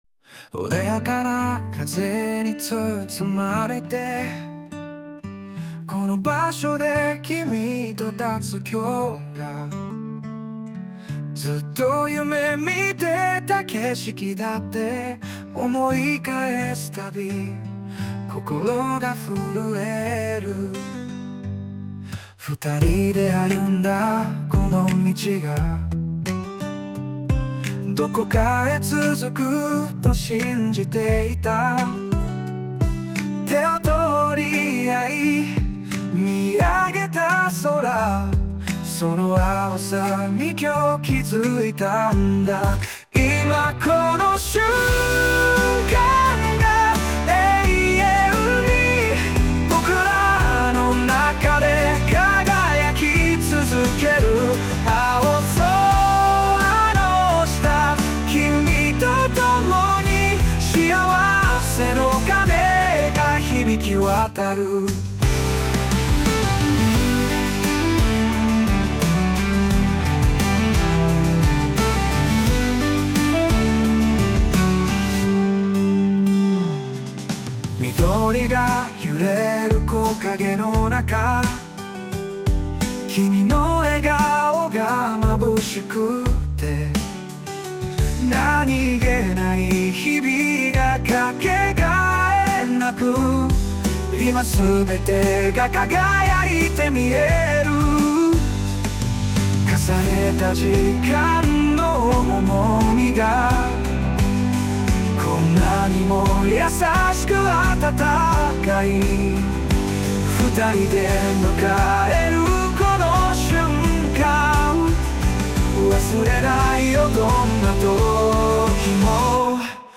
男性ボーカル邦楽 男性ボーカルエンドロール
著作権フリーオリジナルBGMです。
男性ボーカル（邦楽・日本語）曲です。